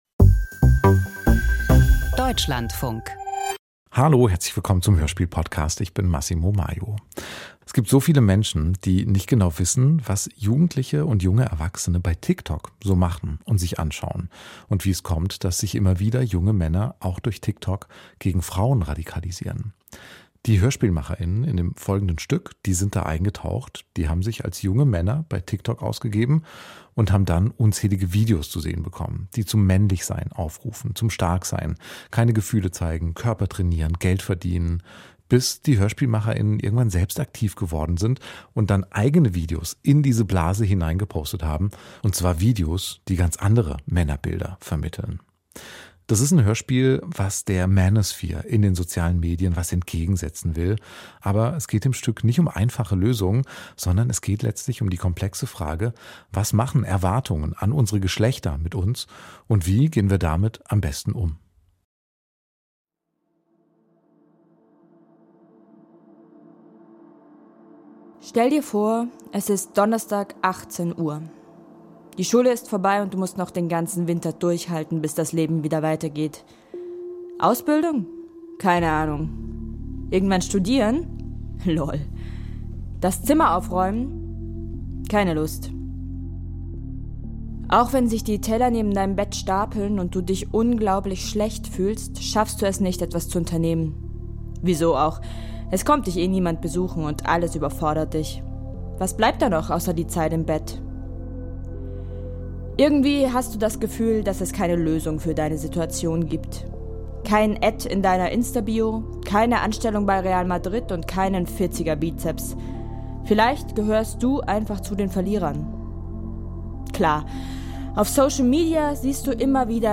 Doku-Hörspiel über Radikalisierung auf TikTok - Hacking the Manosphere